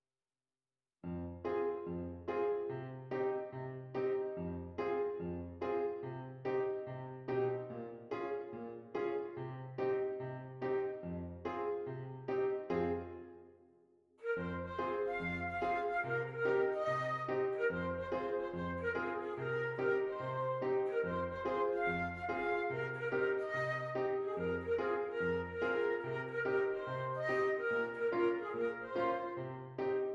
Flute Solo with Piano Accompaniment
Does Not Contain Lyrics
A Flat Major
Moderate swing